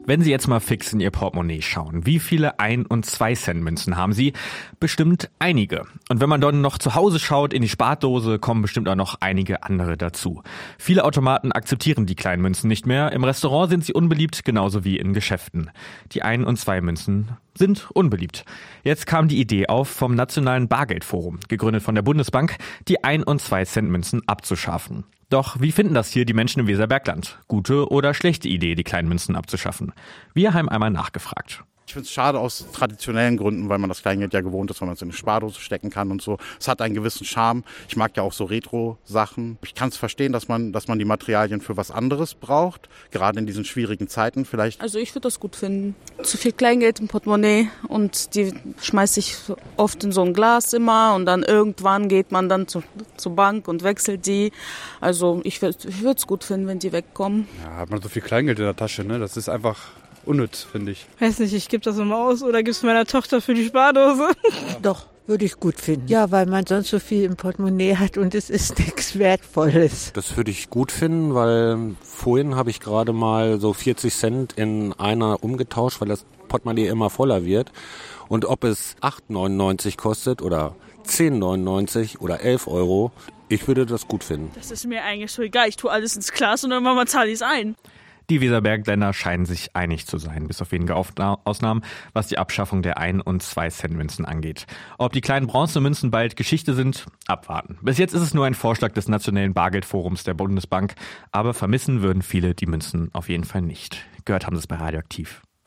Umfrage: Ein- und Zwei-Cent-Münzen abschaffen?